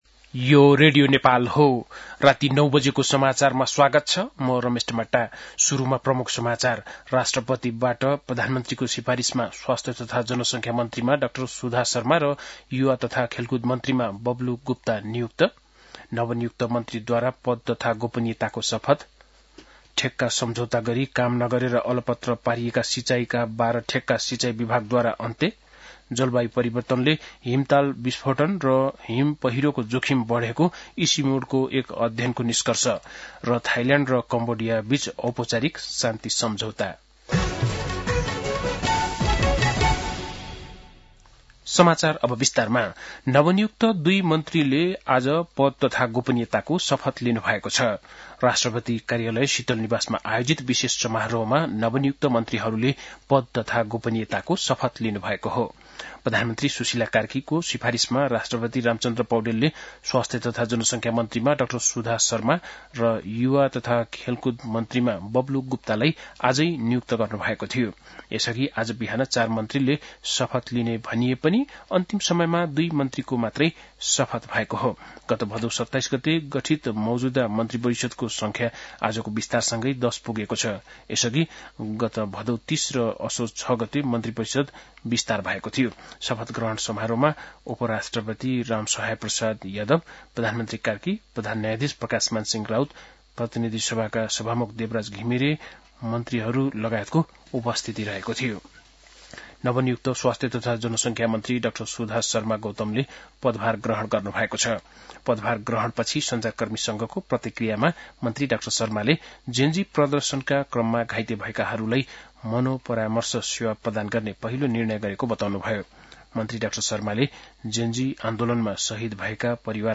बेलुकी ९ बजेको नेपाली समाचार : ९ कार्तिक , २०८२
9-pm-nepali-news-1-1.mp3